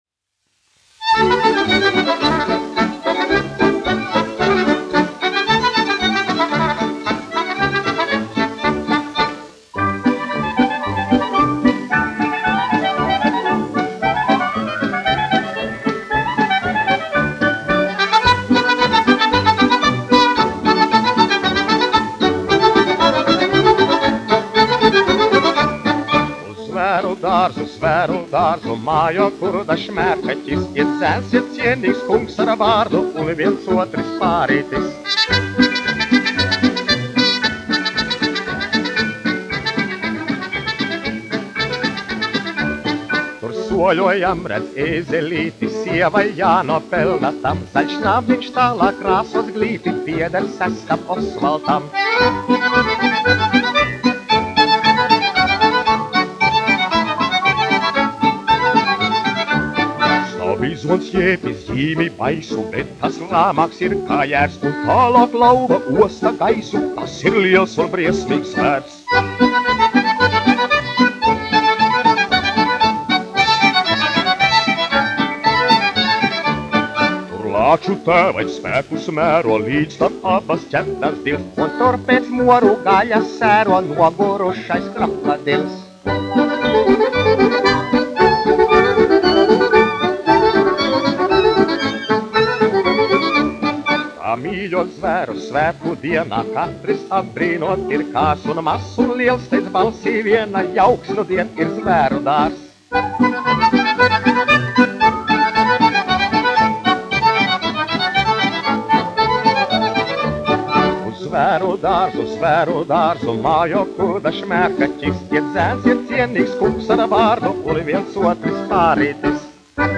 1 skpl. : analogs, 78 apgr/min, mono ; 25 cm
Polkas
Populārā mūzika -- Latvija
Skaņuplate